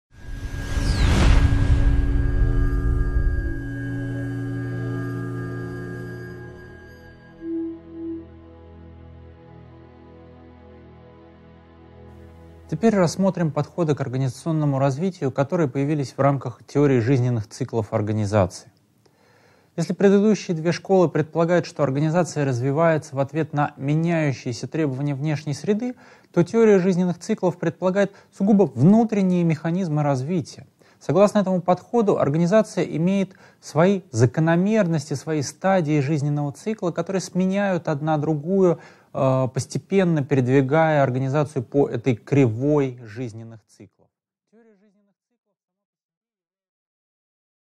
Аудиокнига 10.3. Развитие организации в теории жизненных циклов | Библиотека аудиокниг